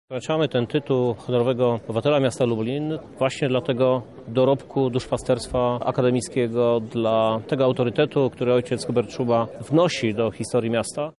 To bardzo ważna postać – mówi Krzysztof Żuk, prezydent miasta.